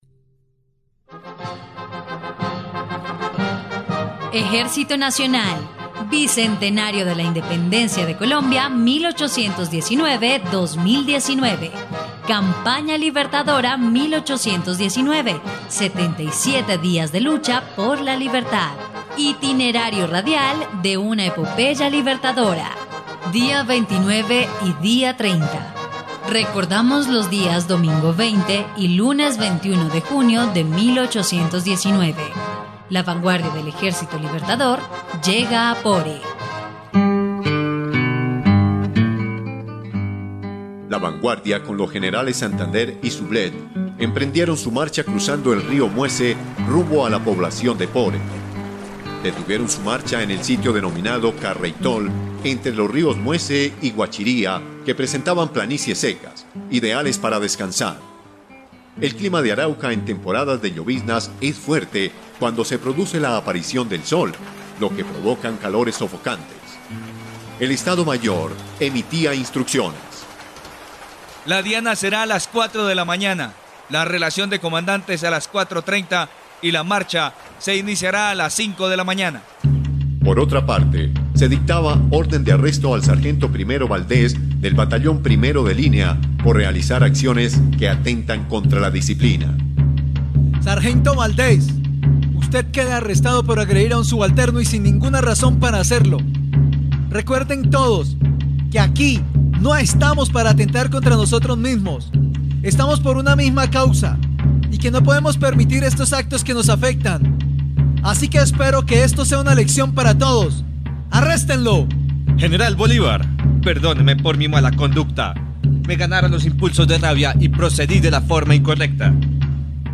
dia_29_y_30_radionovela_campana_libertadora.mp3